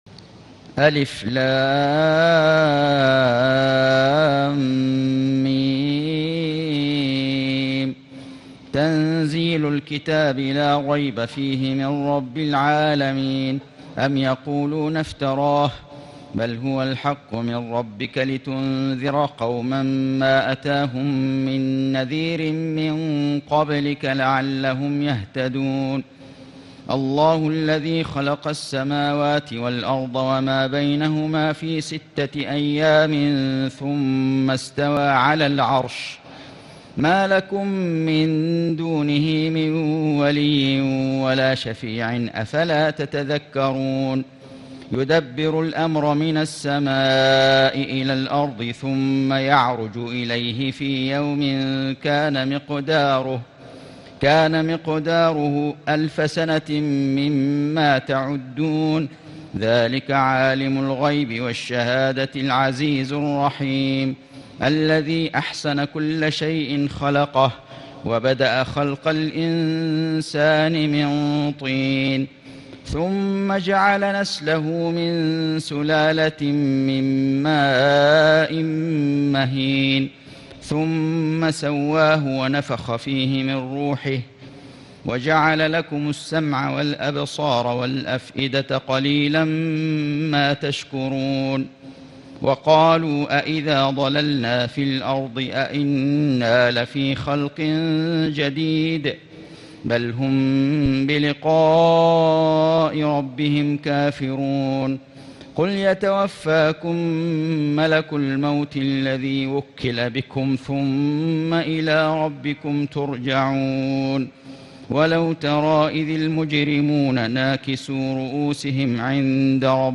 سورة السجدة > السور المكتملة للشيخ فيصل غزاوي من الحرم المكي 🕋 > السور المكتملة 🕋 > المزيد - تلاوات الحرمين